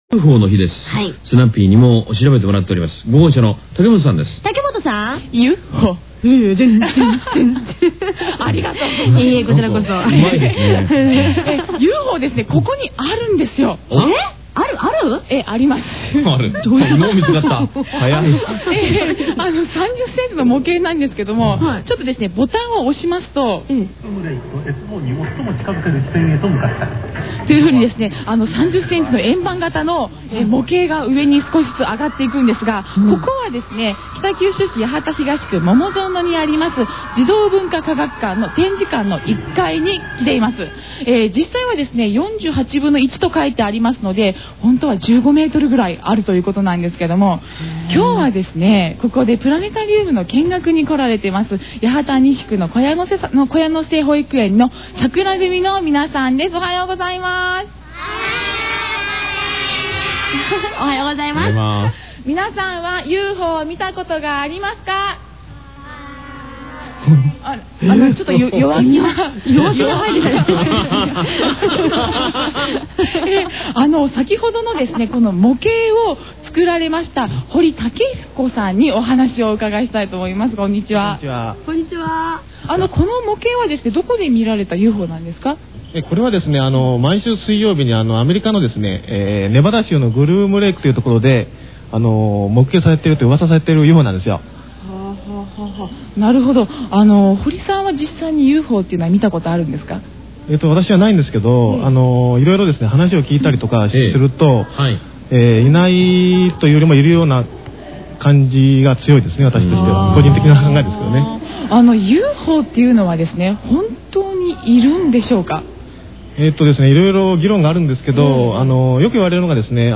ラジオに出演しUFOについての解説をおこないました。